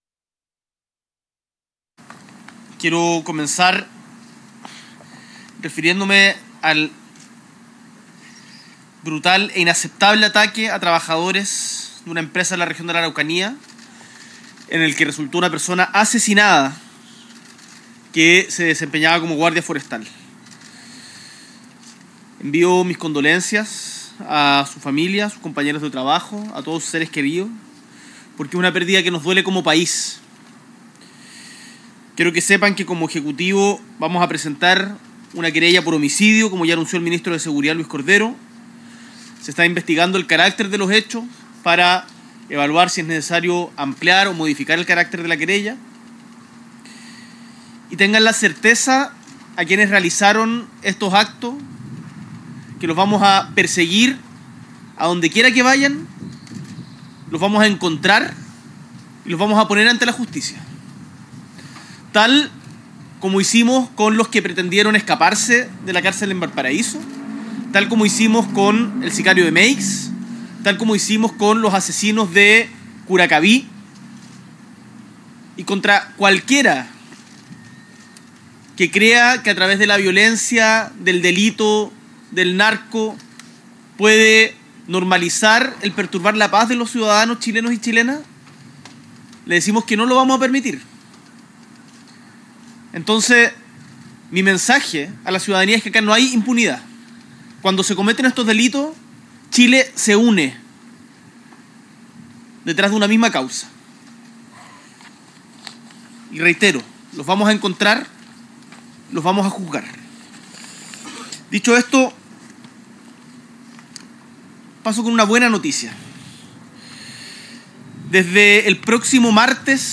S.E. el Presidente de la República, Gabriel Boric Font, encabeza un encuentro con el Comité Policial de Seguridad Pública y vecinos y vecinas de la comuna de Lo Prado